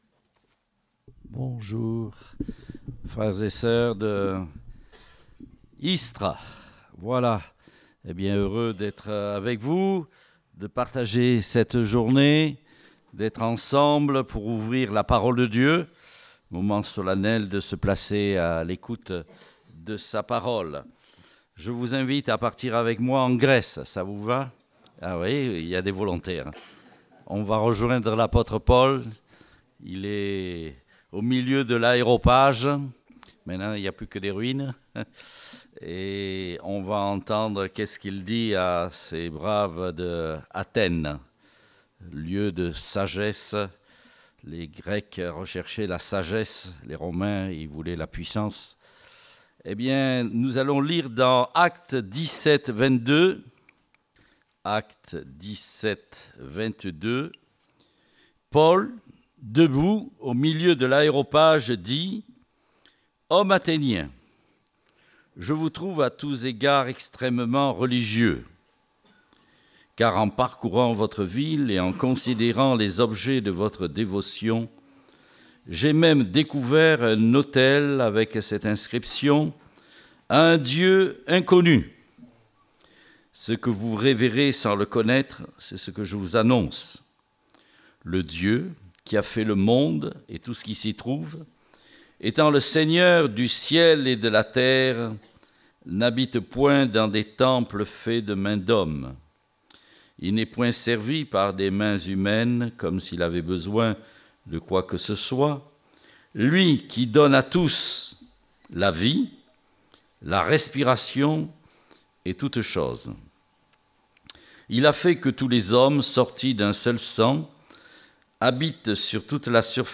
Date : 4 novembre 2018 (Culte Dominical)